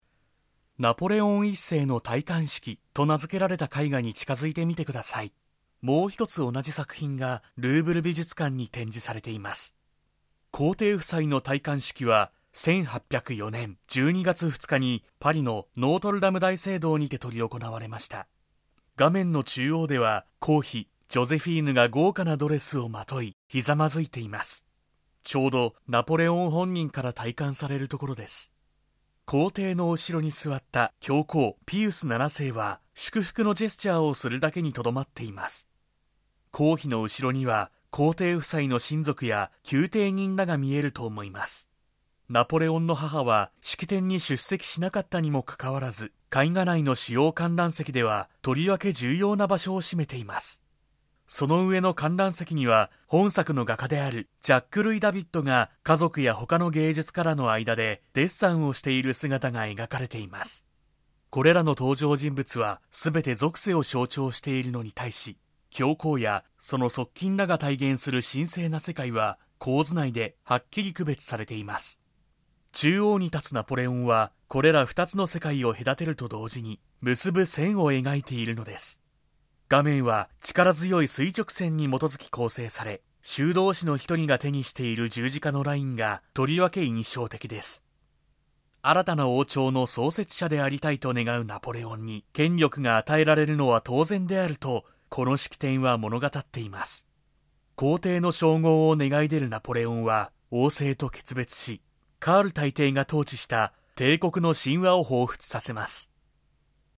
AUDIO DEMOS
2. Guide Versailles Palace 1:47